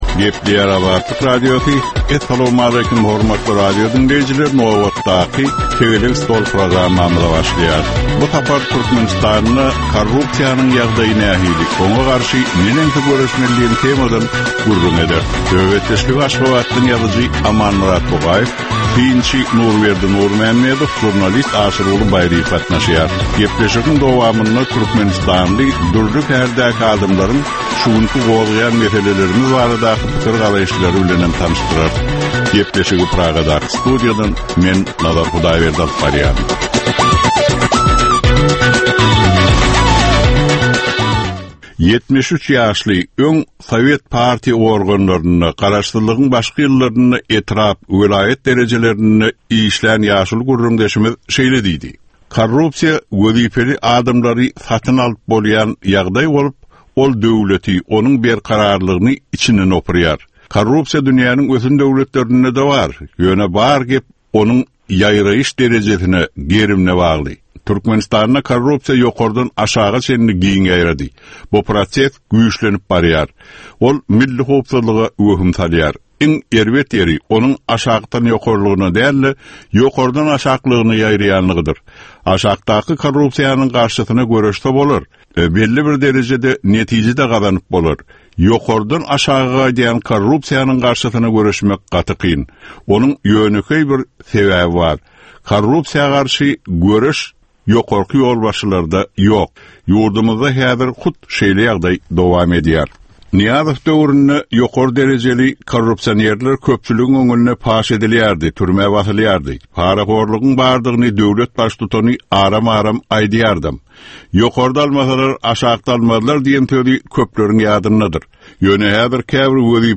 Jemgyýetçilik durmuşynda bolan ýa-da bolup duran soňky möhum wakalara ýa-da problemalara bagyşlanylyp taýýarlanylýan ýörite “Tegelek stol” diskussiýasy. Bu gepleşikde syýasatçylar, analitikler we synçylar anyk meseleler boýunça öz garaýyşlaryny we tekliplerini orta atýarlar.